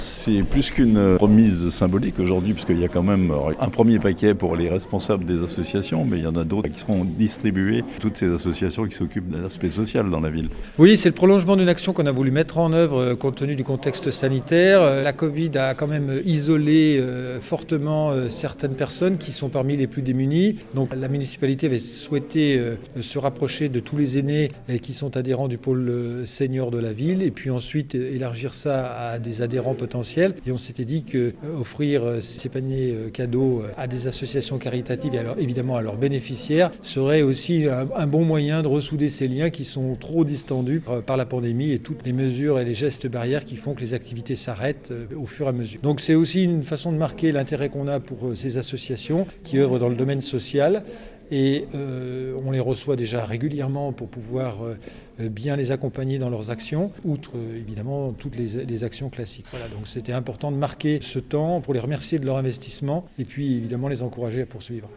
Distribution de paniers gourmands aux responsables des associations caritatives de Thonon (interview)